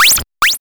8-bit arcade atari chiptune game jump life mario sound effect free sound royalty free Gaming